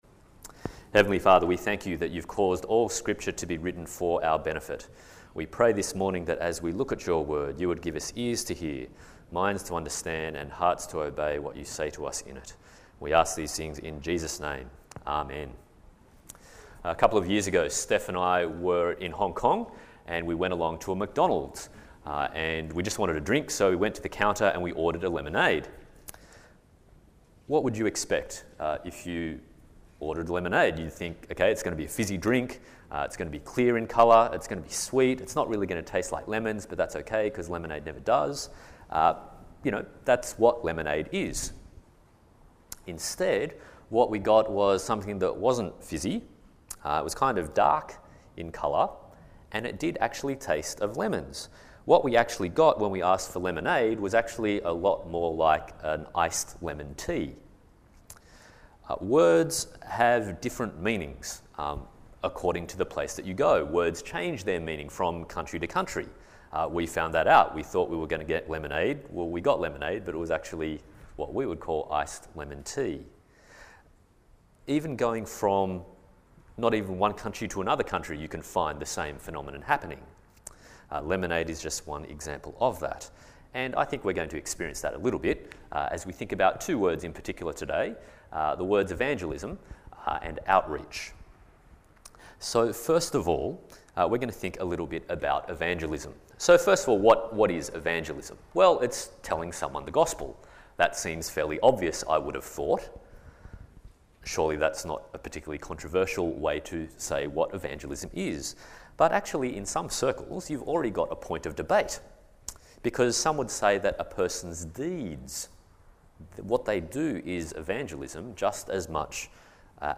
Series: A Single Sermon Passage: 1 Corinthians 15:1-11, 2 Samuel 18:19-33 Service Type: Sunday Morning